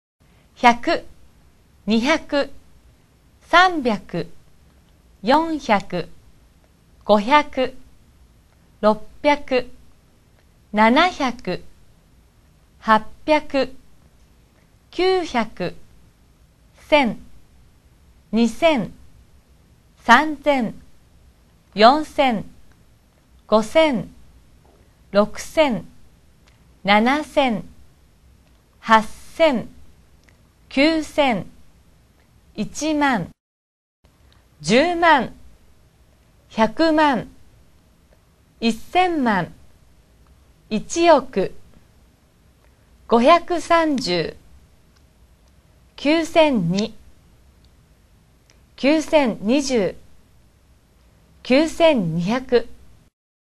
注意：”100″ “1,000”在日語念 “百” “千”，前面不加”1″。